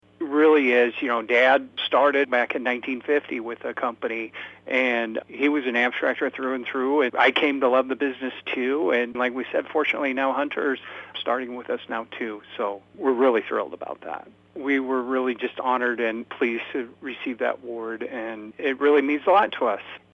The Carroll Chamber of Commerce hosted its annual banquet Thursday evening and presented awards to community leaders, volunteers, and local businesses.